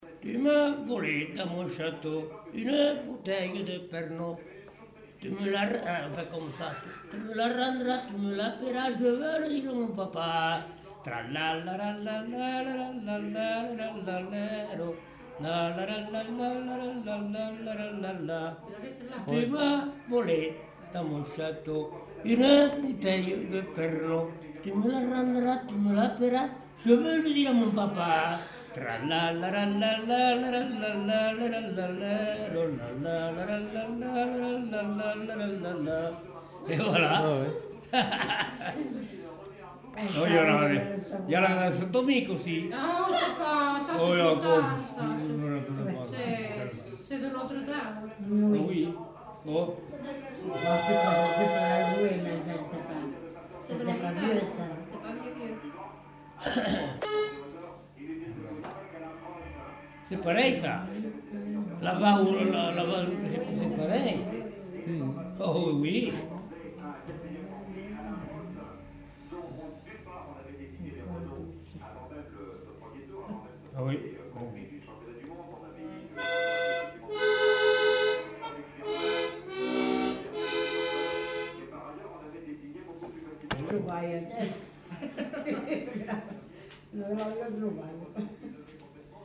Aire culturelle : Savès
Lieu : Pavie
Genre : chant
Effectif : 1
Type de voix : voix d'homme
Production du son : chanté ; fredonné
Danse : polka des bébés